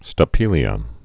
(stə-pēlē-ə)